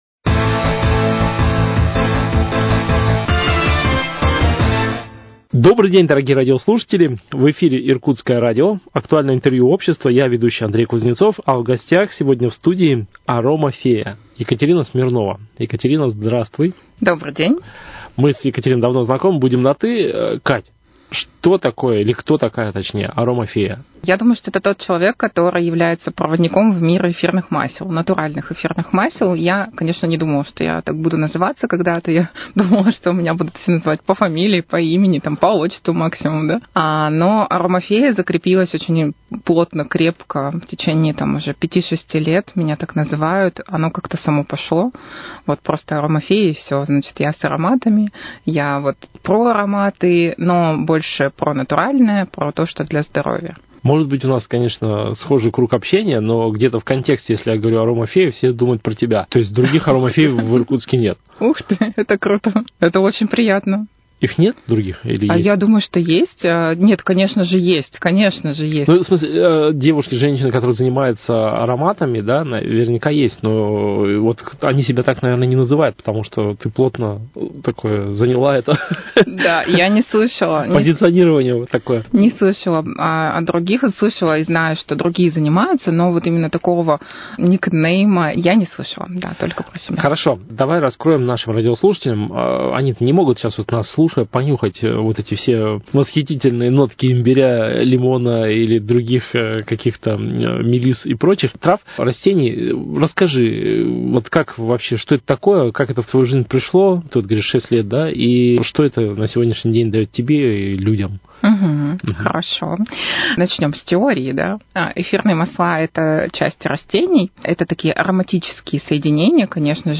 Актуальное интервью: О влиянии ароматов на нашу жизнь